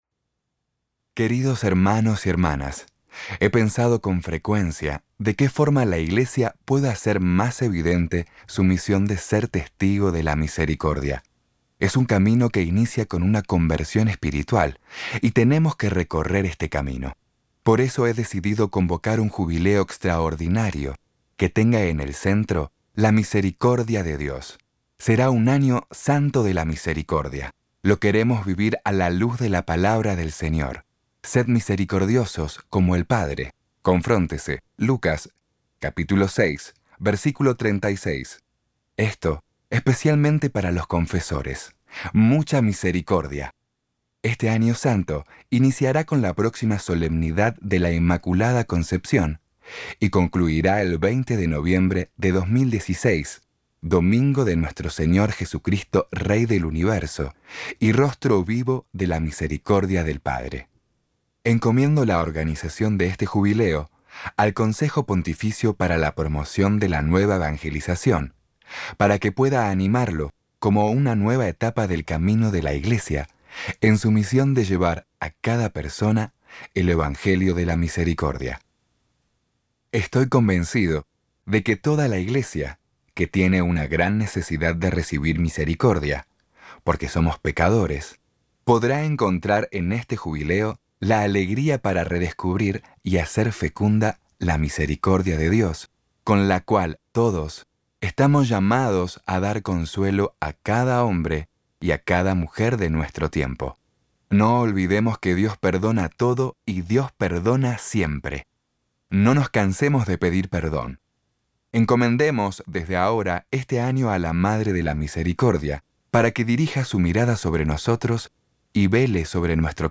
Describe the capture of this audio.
3.9 Hrs. – Unabridged